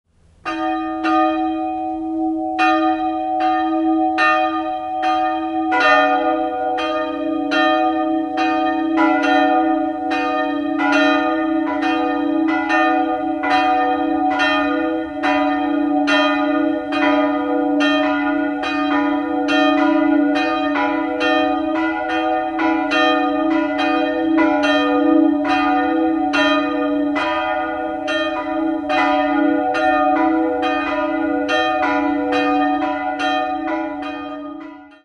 Jahrhundert umgebaut wurde. 2-stimmiges Große-Terz-Geläute: h'-dis'' Die größere Glocke stammt von Ignaz Friedrich Pascolini (Eichstätt) aus dem Jahr 1839, die kleinere ist ein Werk von Karl Hamm (Regensburg) aus dem Jahr 1932.